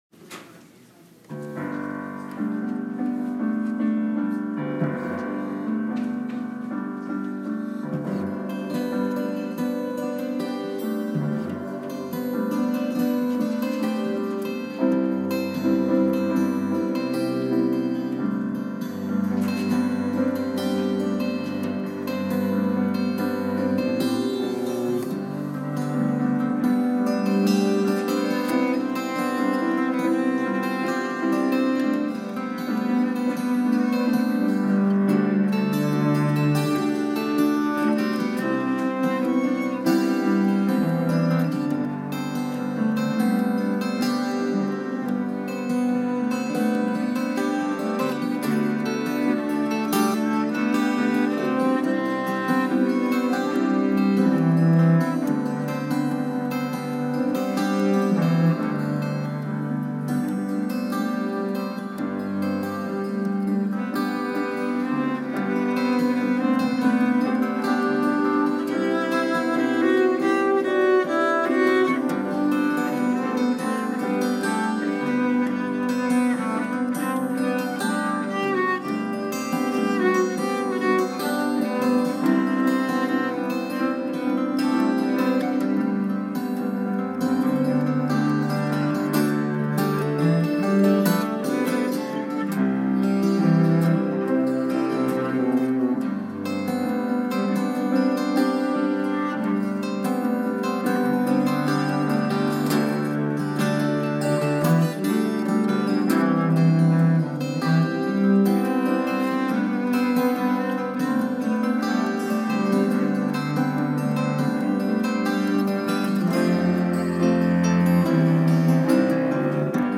vocals, cello, keyboards, acoustic bass, guitar
guitars, cuatro/charango, Irish bouzouki, vihuela, saxophone
Guest pianist